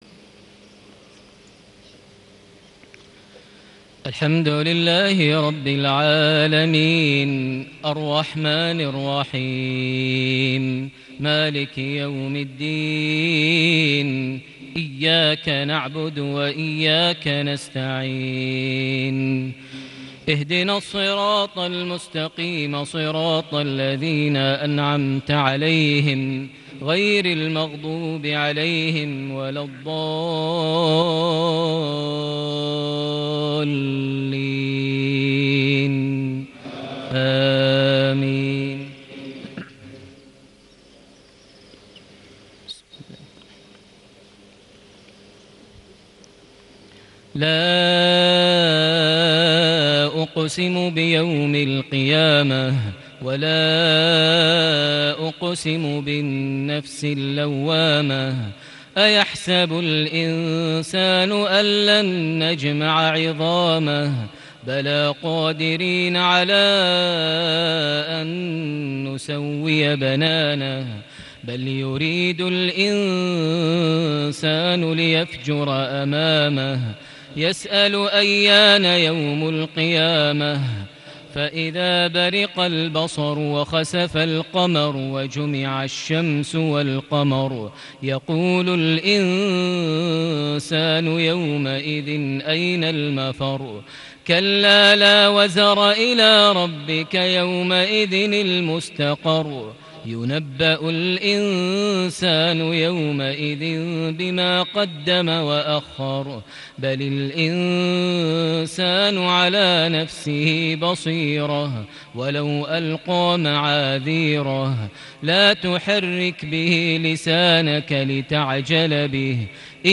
صلاة المغرب ١٨ ربيع الآخر ١٤٣٨هـ سورة القيامة > 1438 هـ > الفروض - تلاوات ماهر المعيقلي